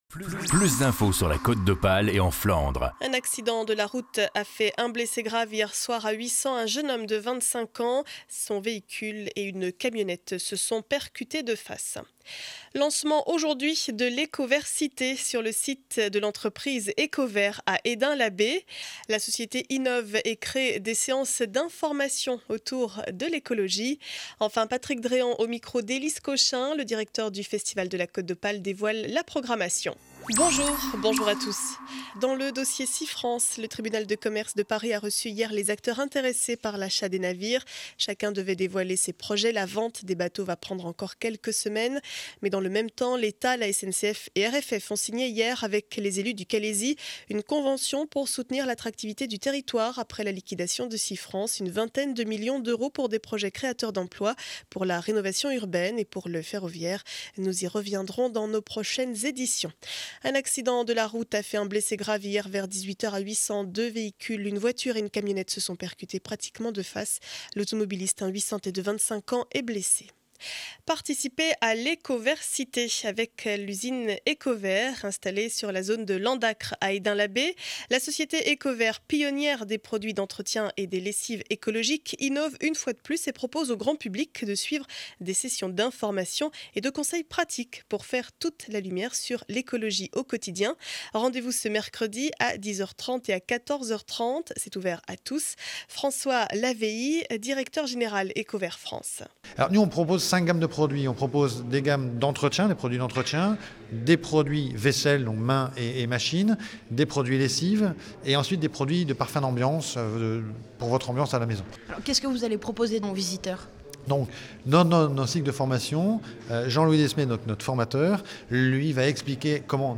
Journal du mercredi 04 avril 2012 7 heures 30 édition du Boulonnais.